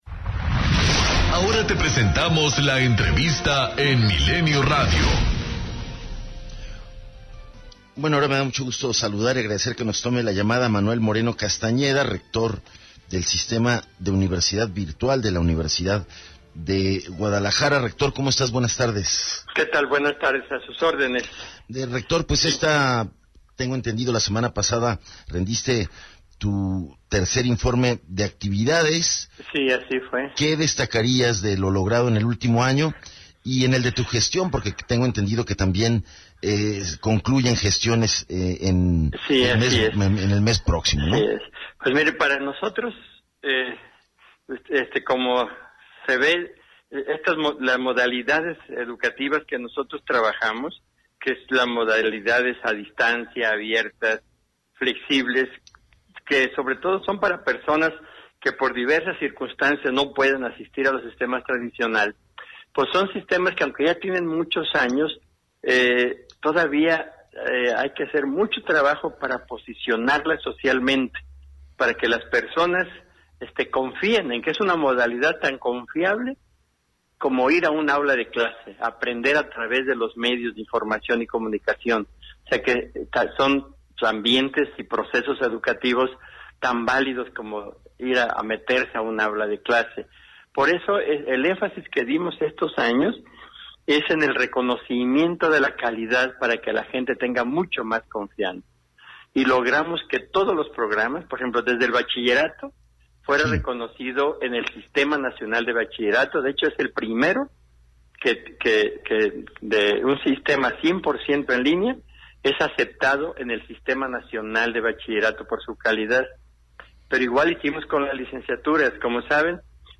ENTREVISTA 140316